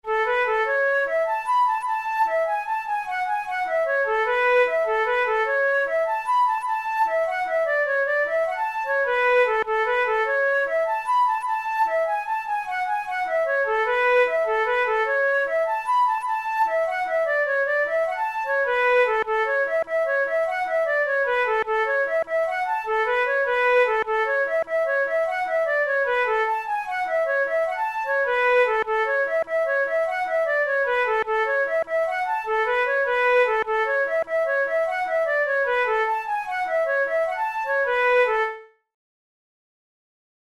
Traditional Irish jig